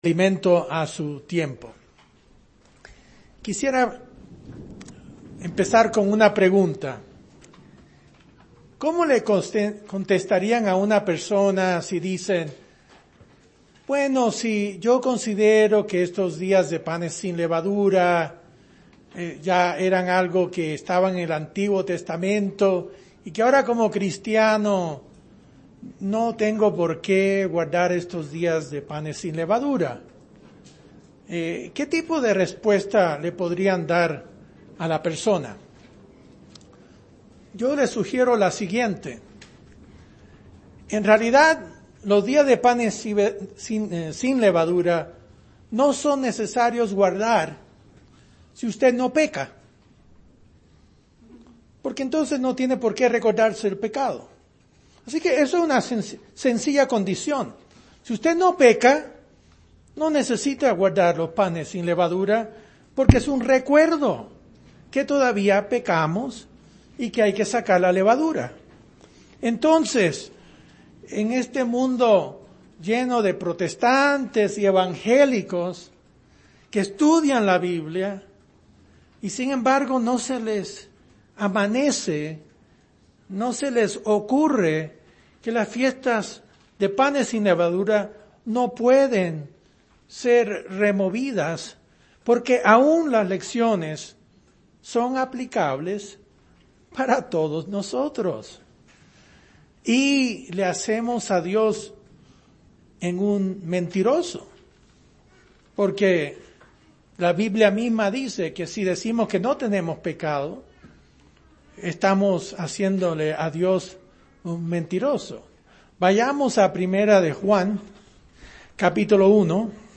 Mensaje entregado el 6 de abril de 2018.